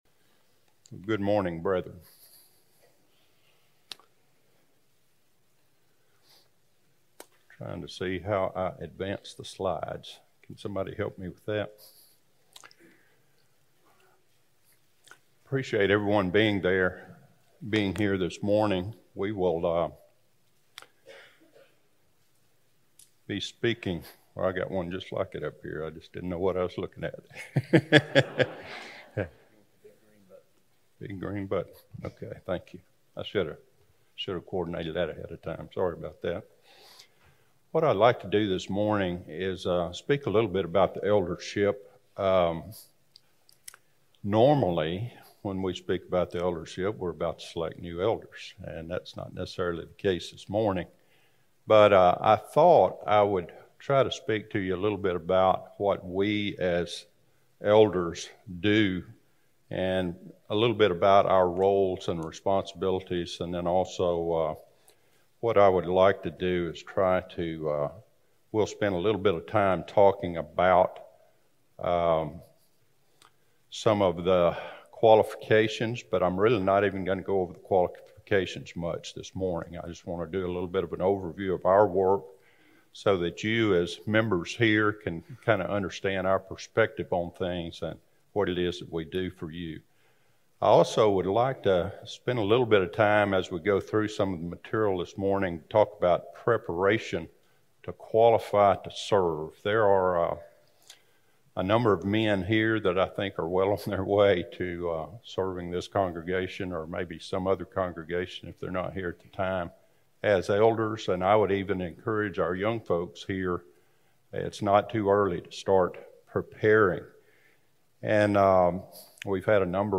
A sermon recorded on July 24, 2022.